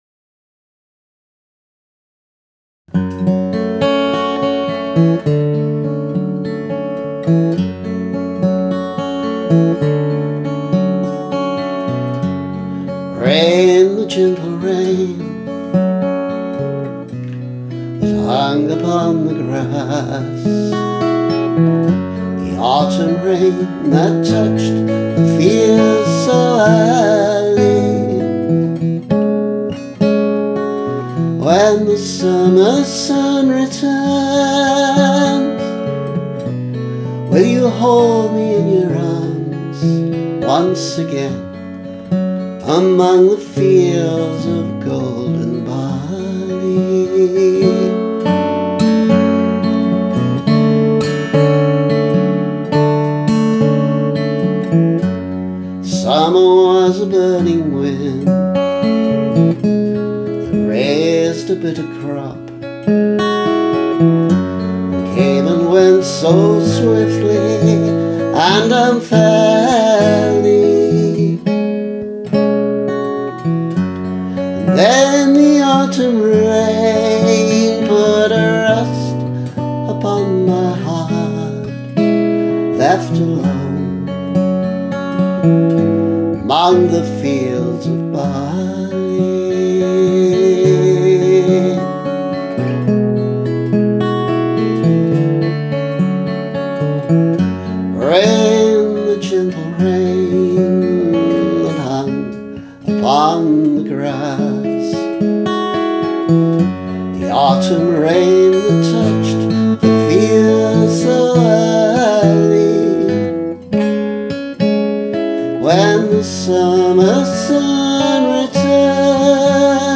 My entry for the July 2020 Trad2Mad competition for unaccompanied singers.
Audio capture, mastered to raise the volume slightly: